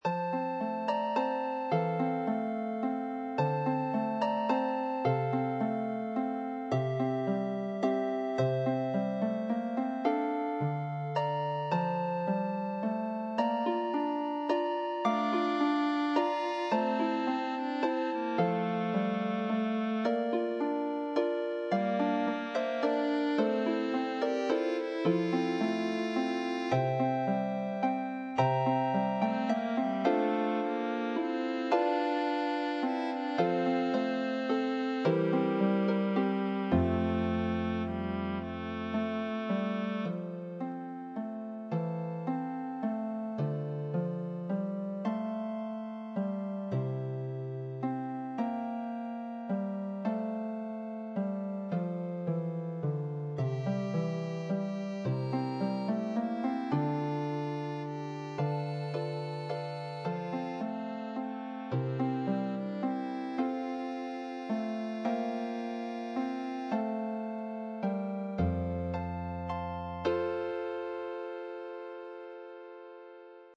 $6.00 ~ (harp and violin or viola or cello)